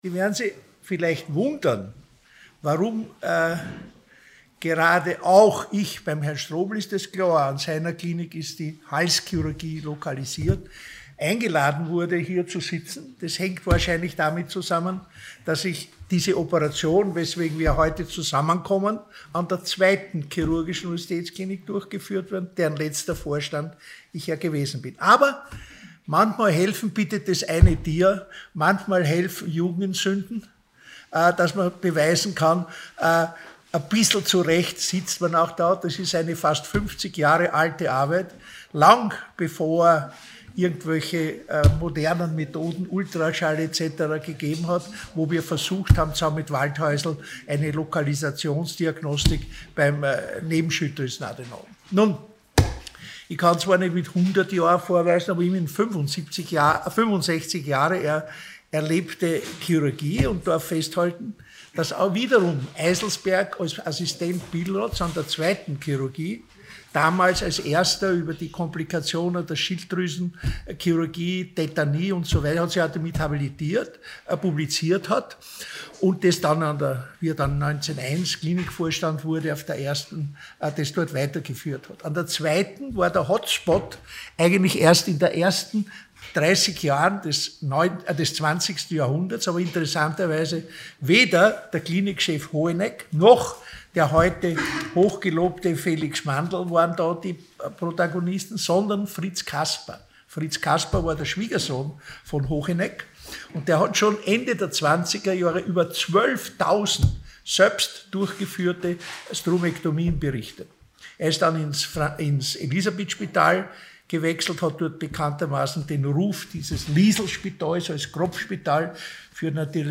Hybridveranstaltung